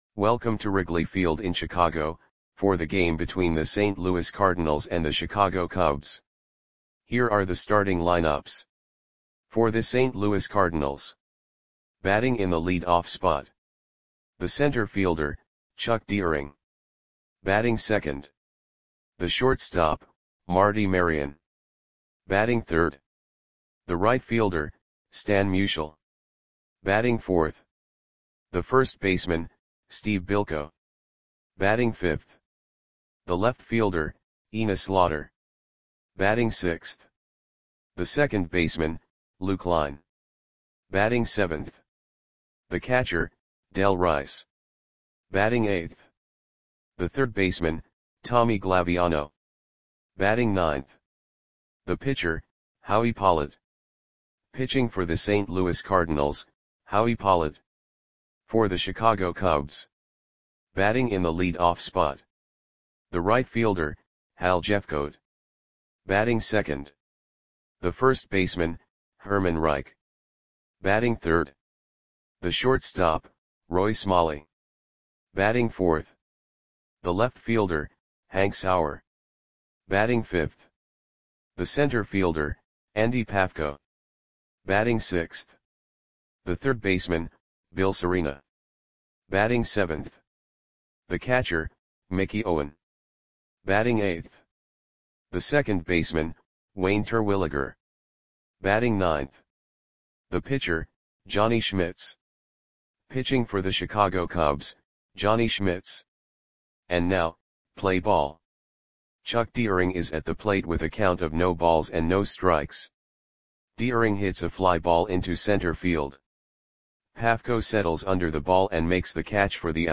Audio Play-by-Play for Chicago Cubs on October 2, 1949
Click the button below to listen to the audio play-by-play.